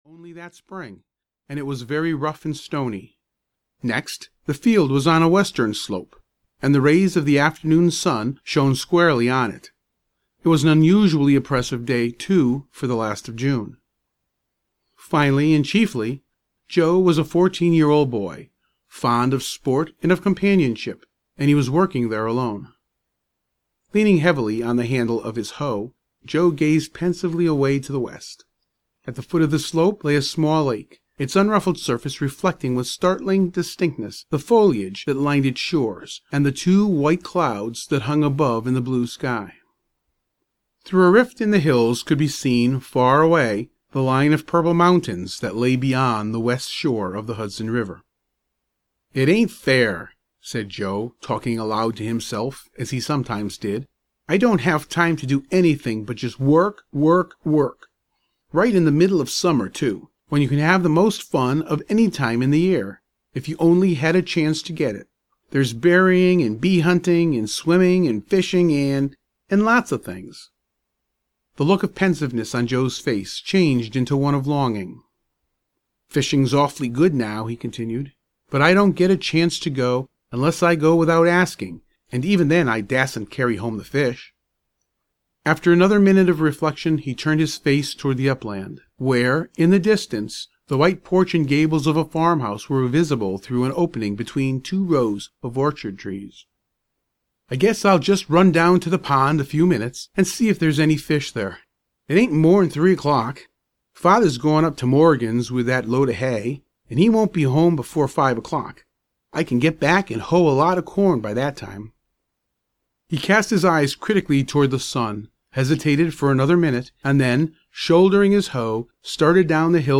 A Tale of the Tow-Path (EN) audiokniha
Ukázka z knihy